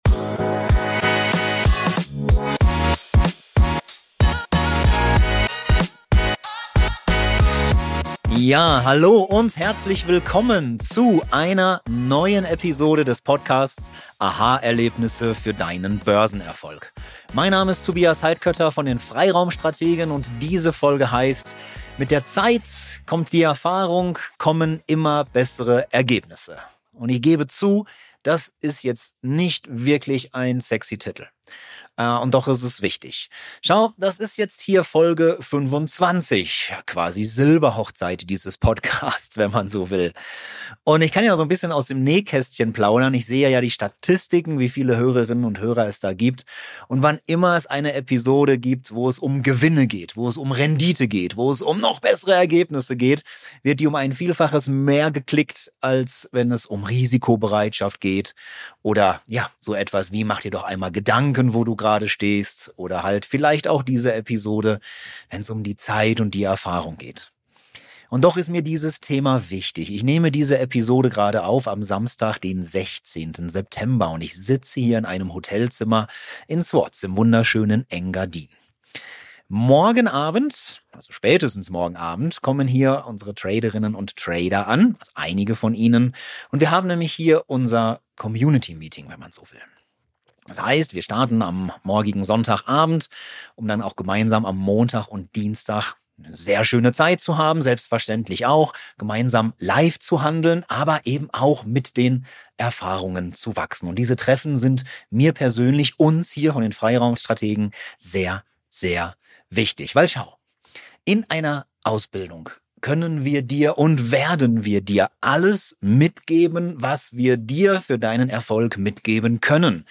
Ich sitze im Hotelzimmer in Zouz im wunderschönen Engadin als ich diese Folge aufnehme.